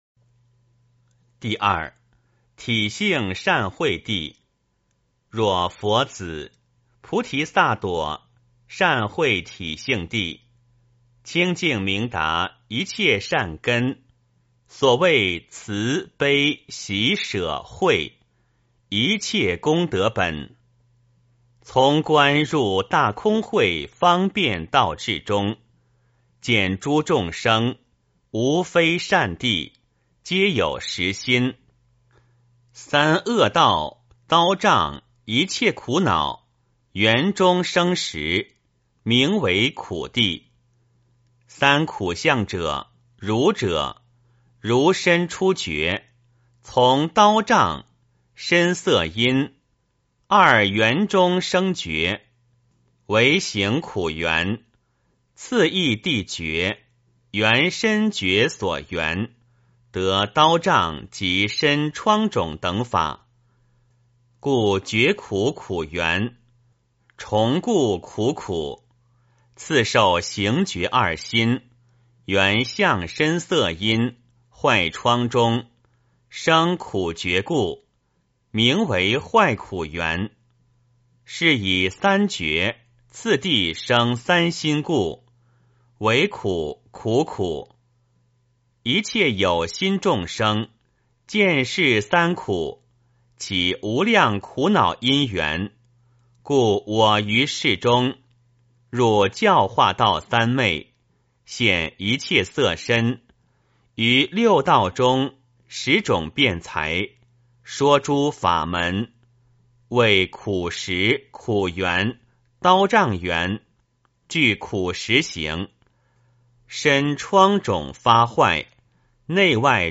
梵网经-十地-体性善慧地 - 诵经 - 云佛论坛